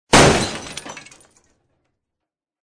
TrafficCollision_2.mp3